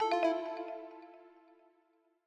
Longhorn 8 - Hardware Remove.wav